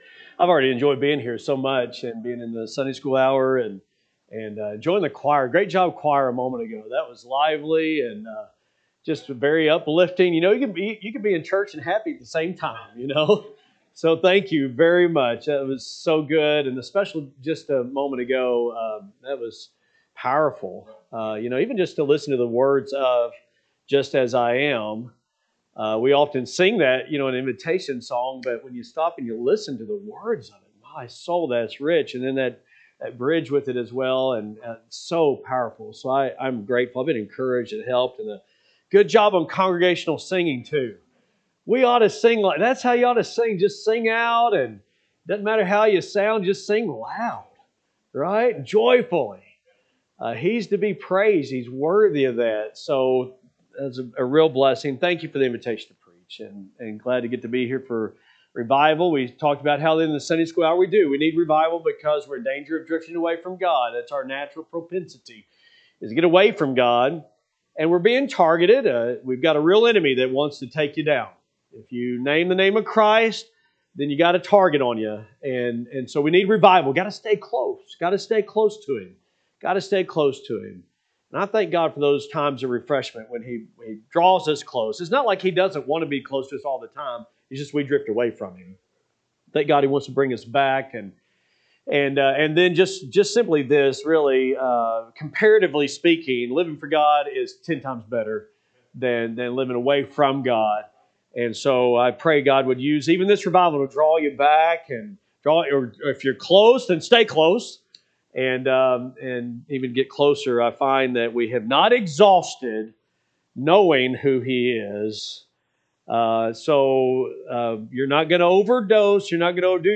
April 12, 2026 am Service Matthew 14:13-33 (KJB) 13 When Jesus heard of it, he departed thence by ship into a desert place apart: and when the people had heard thereof, they followed him on fo…